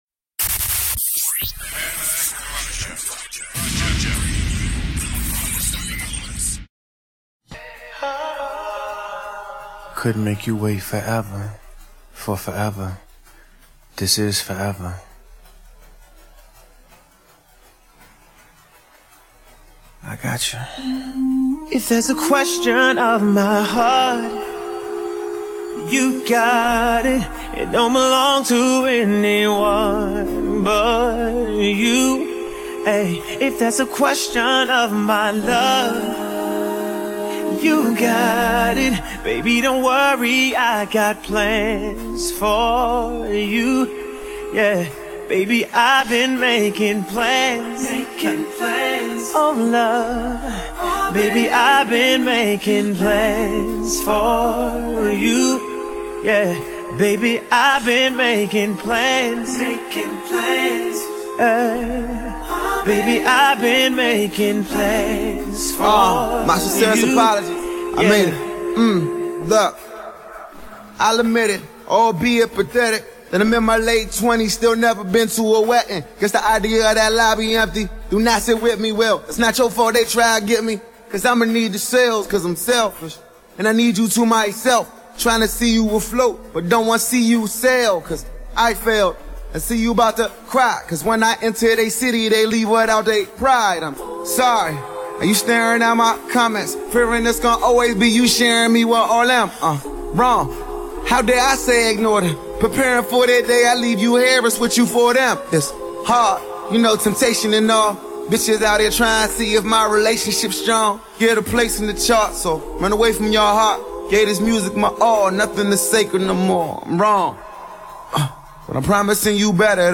SB acapellas (10).mp3